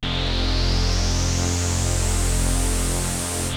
KORG F2  3.wav